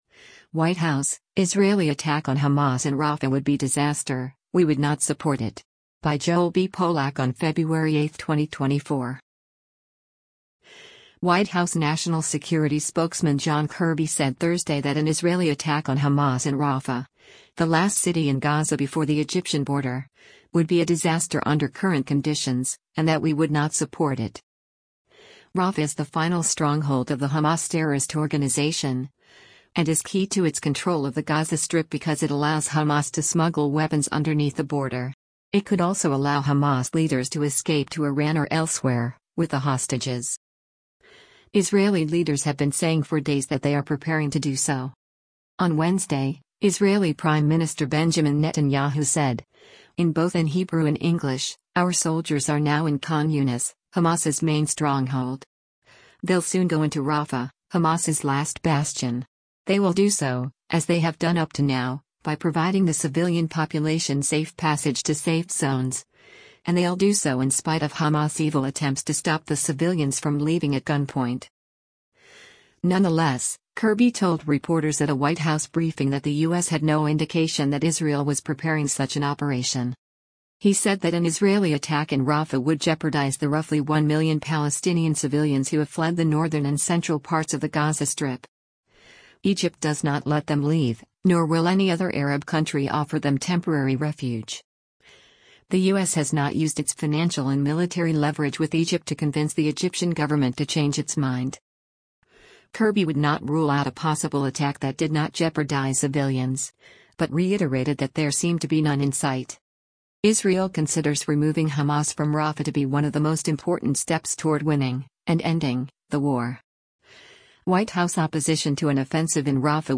Nonetheless, Kirby told reporters at a White House briefing that the U.S. had no indication that Israel was preparing such an operation.